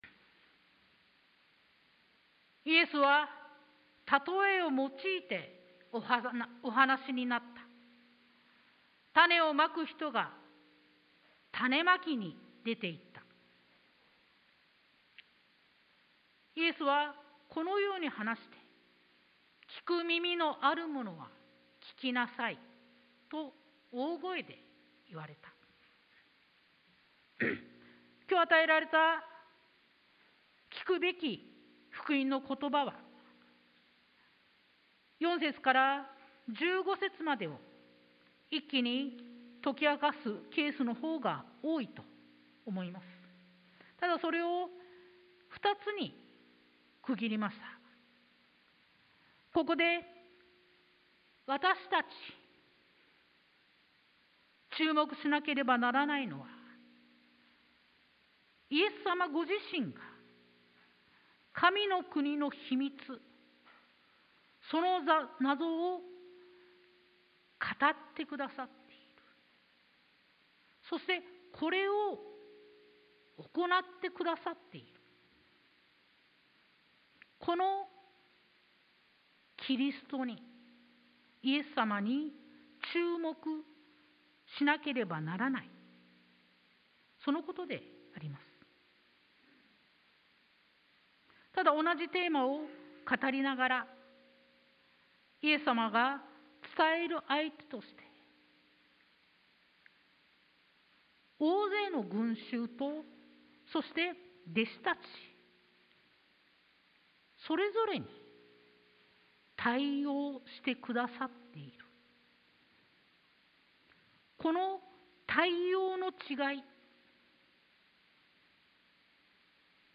sermon-2023-01-29